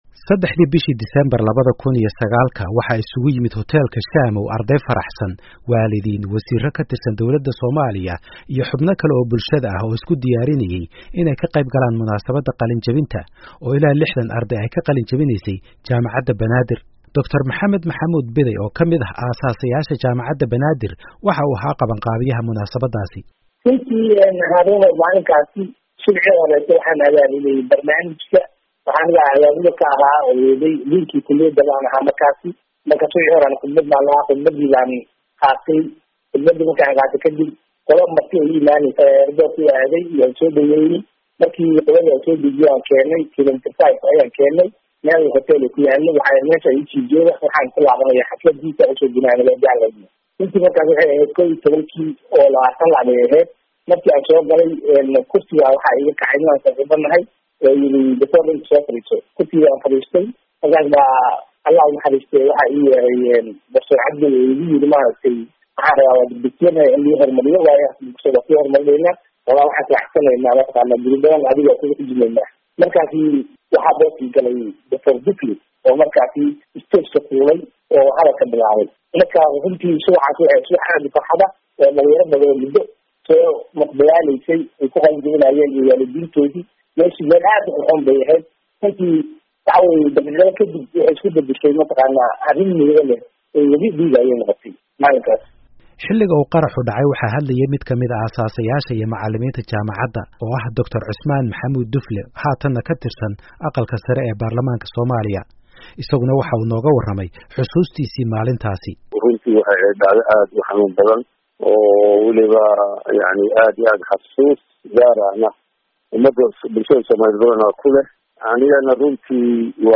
Warbixin: 10 sano kadib qaraxii Hotel Shaamo